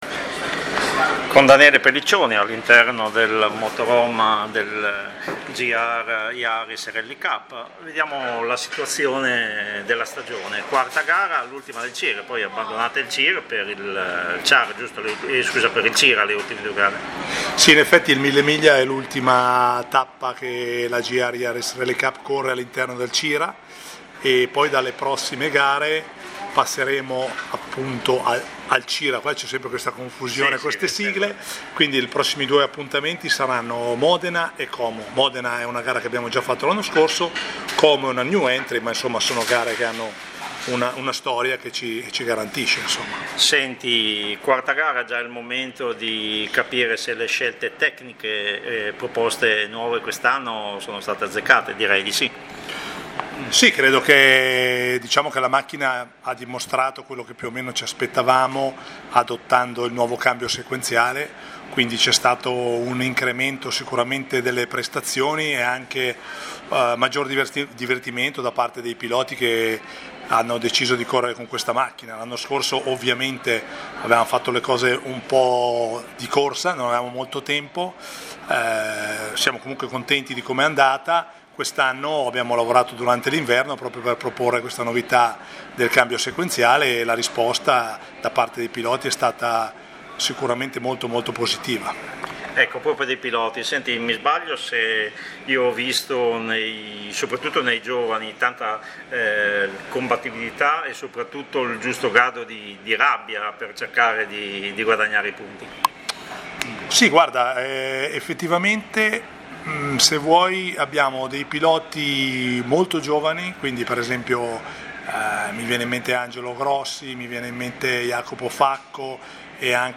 Giovedì - Interviste pre gara